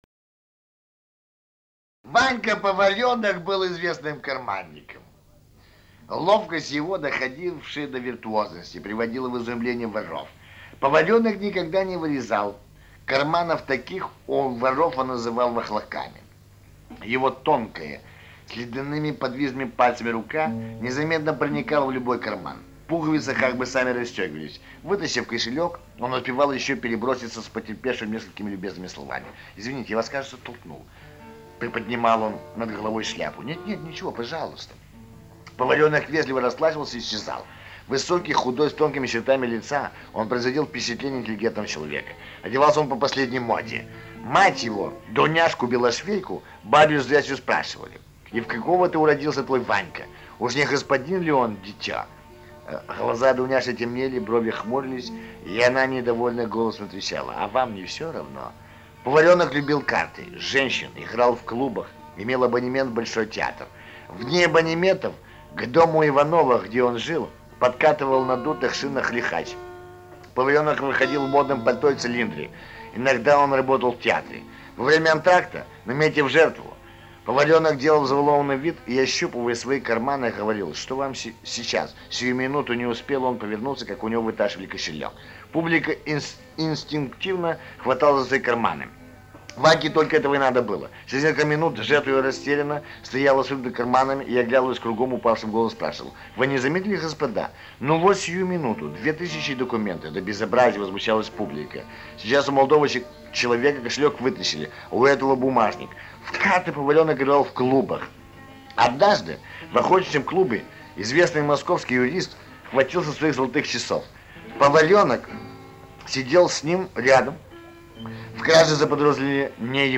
Отрывки из глав рассказа А.И.Вьюркова - "Трущоба", читает Аркадий Северный (избранные литературные композиции из сценарного концерта под гитару -  "Рассказы о московском дне", г.Ленинград 1973-74 г.).